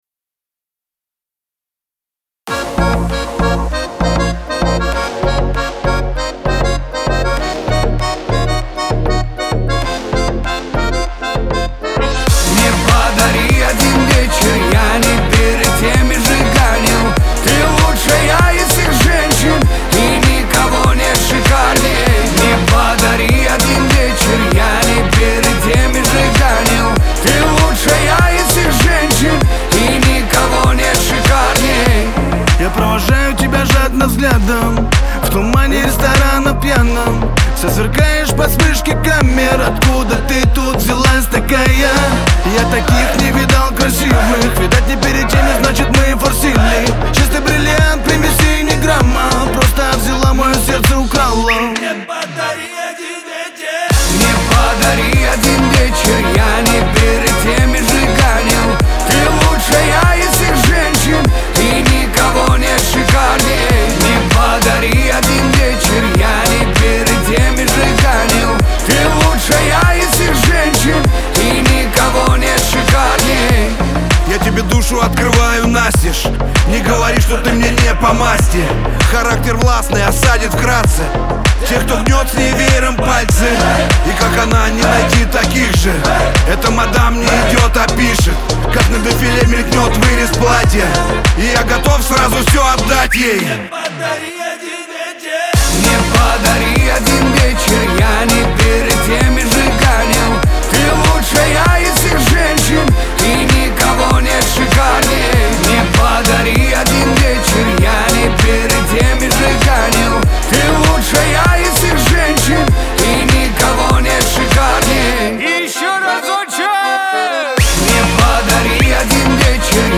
Лирика , дуэт
ХАУС-РЭП